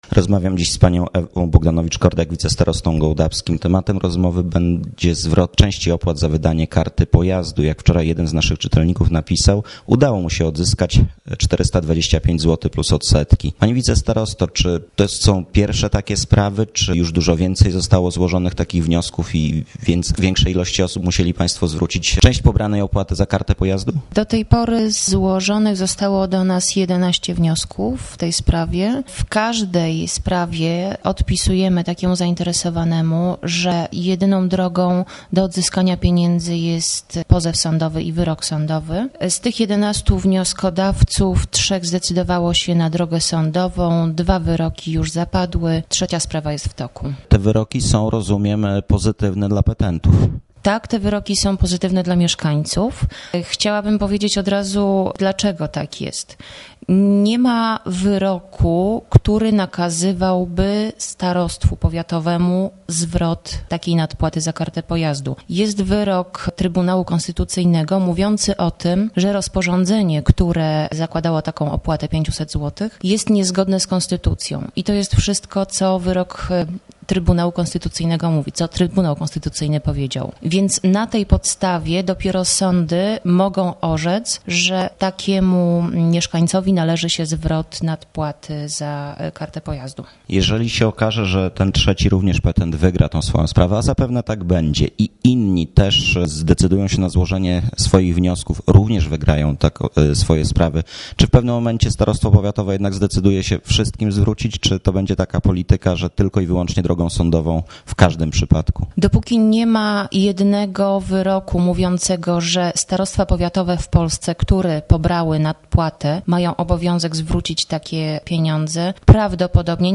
rozmowa z wicestarostą gołdapskim Ewą Bogdanowicz-Kordjak